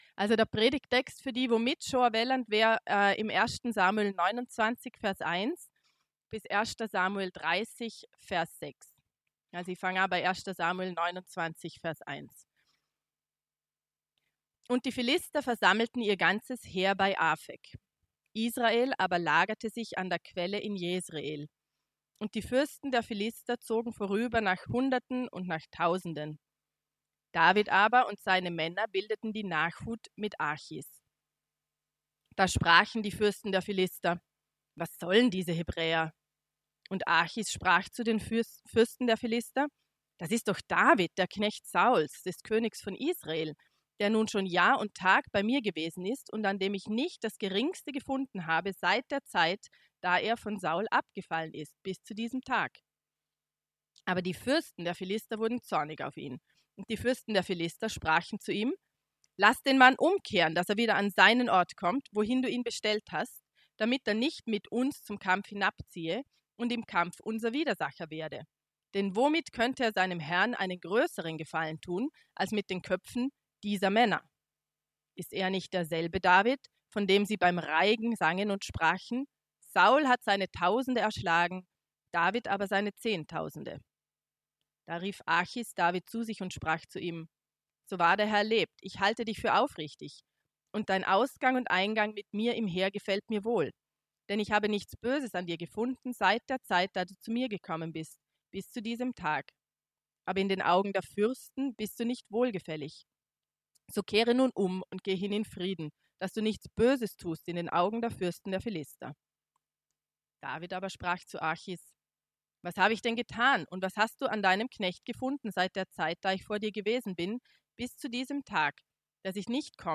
Die erste Serie der Predigtreihe „Auf Königssuche“ durch das Buch 1. Samuel (Kapitel 1-16) fand 2020 statt.